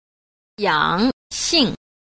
6. 養性 – yǎngxìng – dưỡng tính